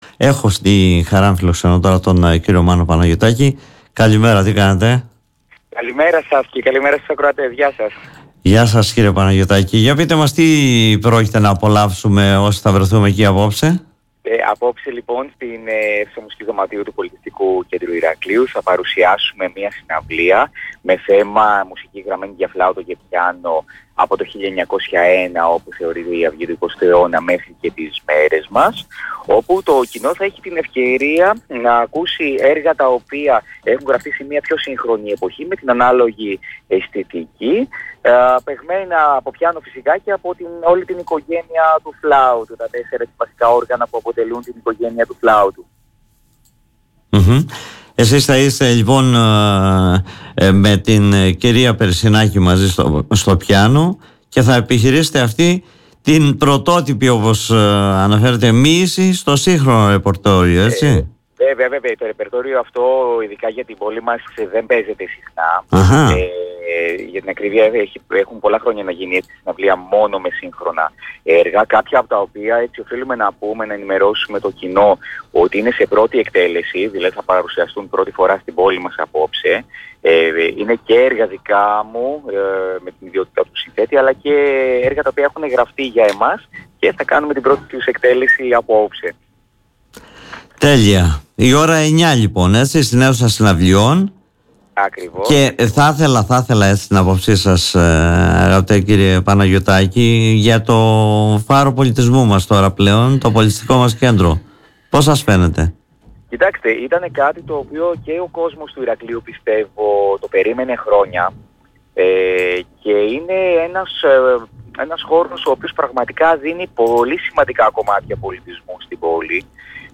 Ακούστε τον κ.